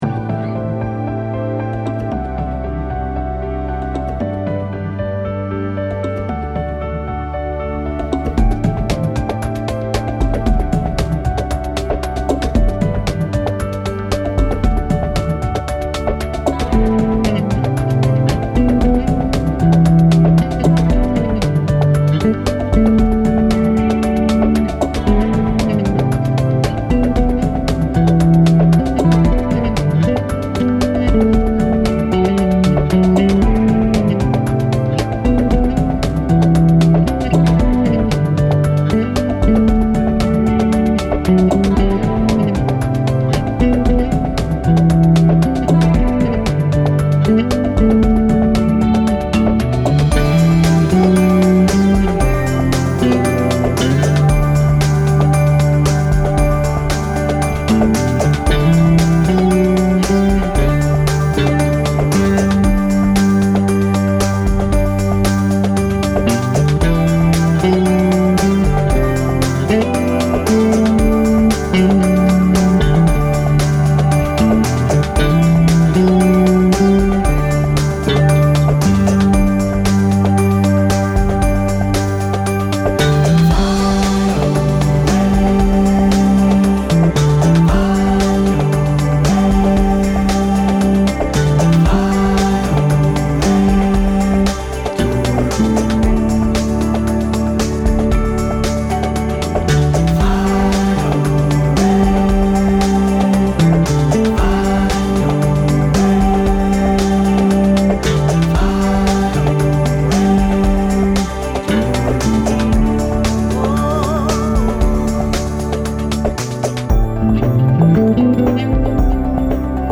Professional Bassist